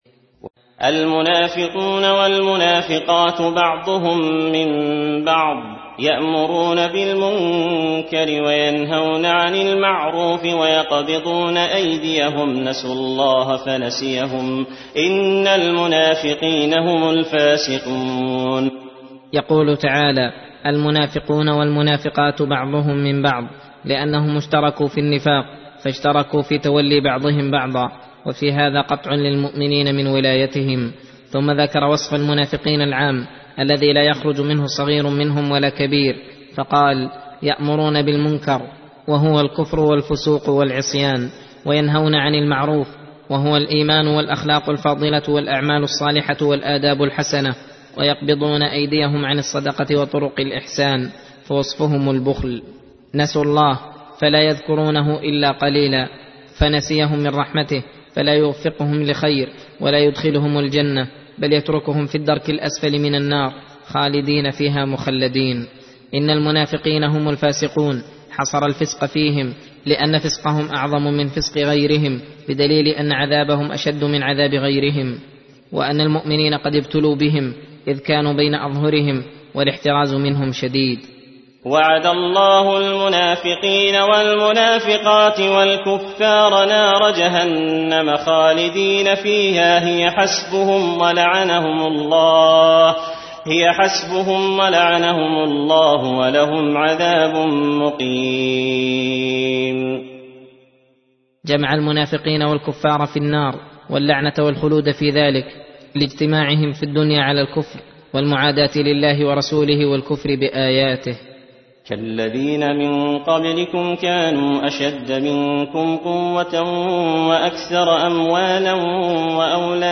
درس (21) : تفسير سورة التوبة (67-85)